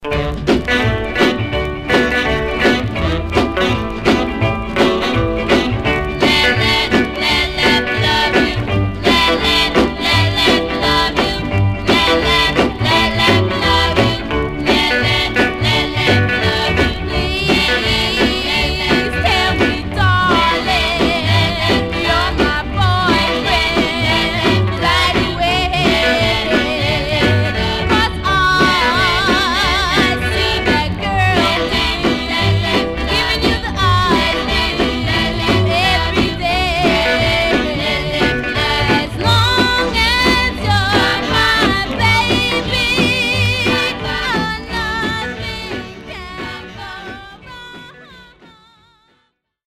Mono
Black Female Group